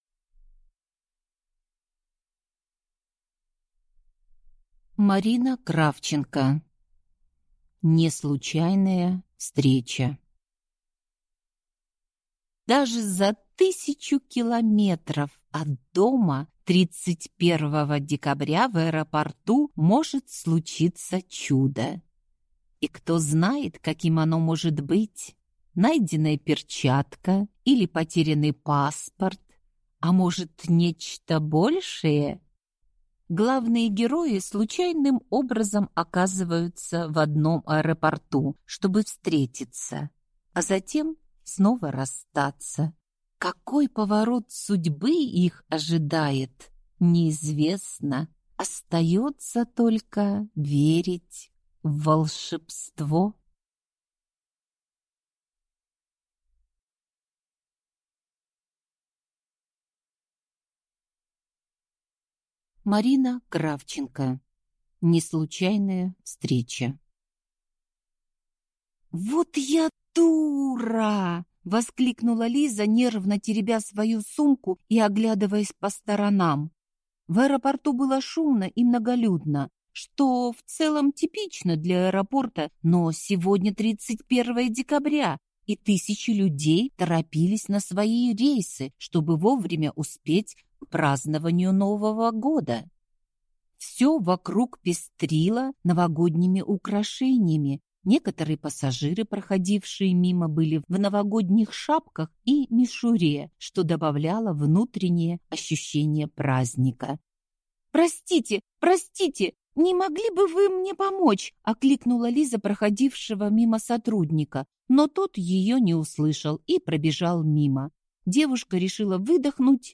Аудиокнига Не случайная встреча | Библиотека аудиокниг